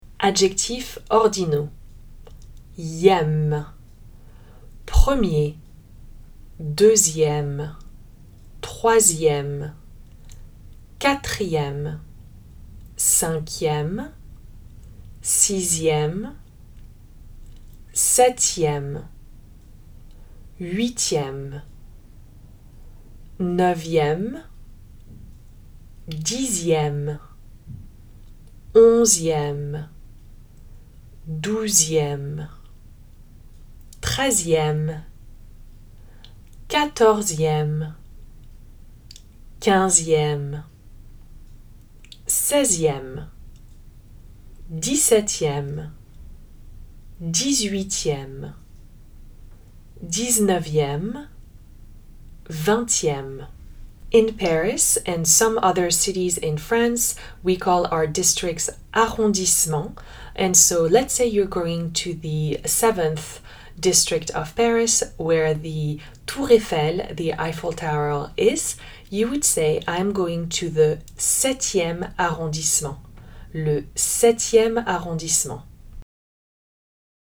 If you'd like to hear how each number sounds in French, you can listen